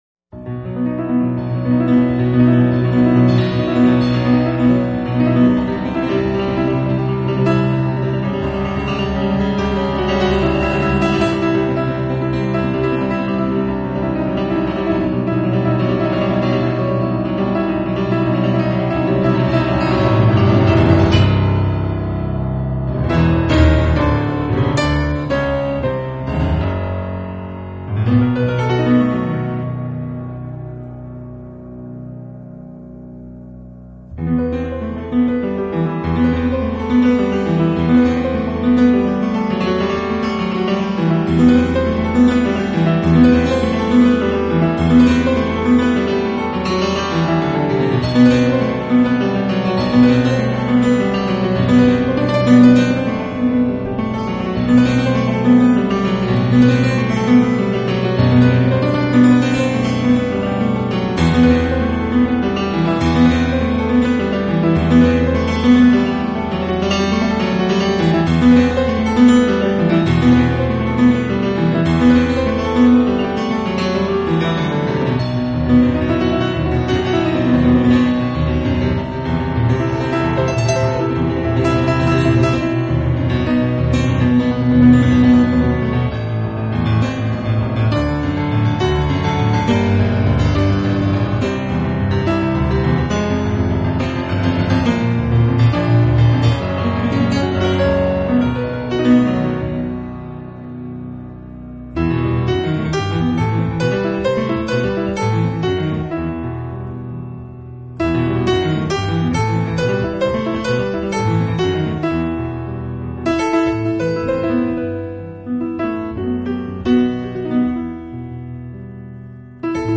【钢琴纯乐】